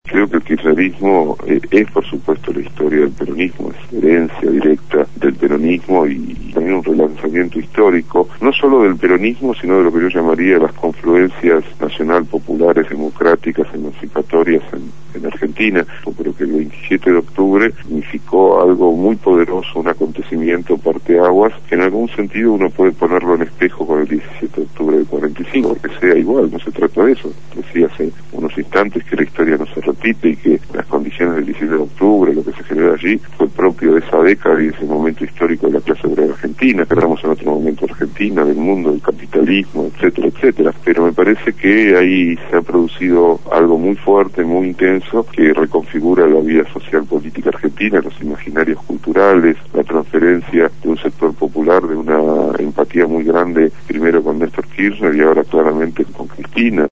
Ricardo Forster fue entrevistado en el programa «Punto de Partida» (lunes a viernes de 7 a 9 de la mañana) habló sobre el contexto histórico- político del golpe de estado cívico militar del 24 de marzo de 1976 y sobre el significado social en la memoria colectiva que tiene esta fecha en el año 2011.